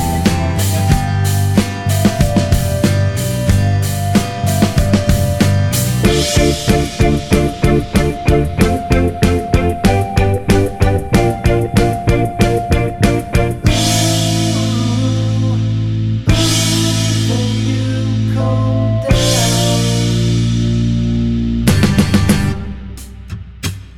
no Backing Vocals Indie / Alternative 3:38 Buy £1.50